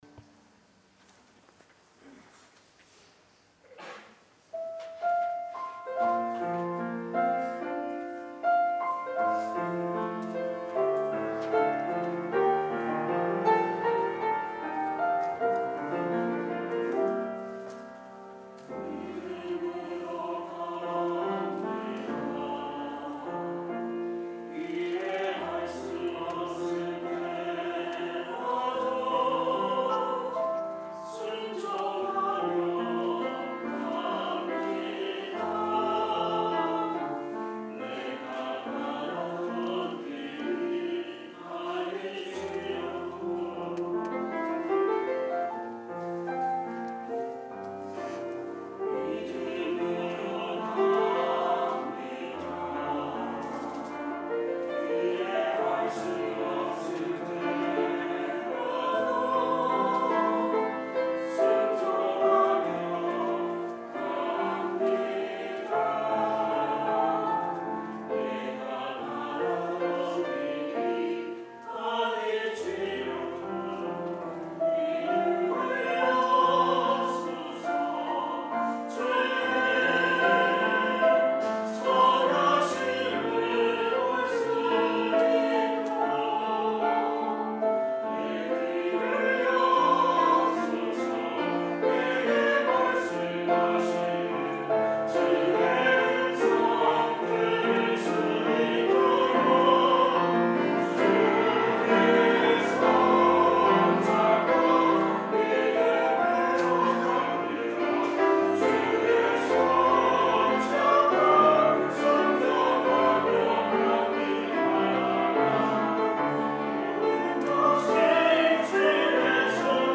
This entry was posted in 주일찬양듣기.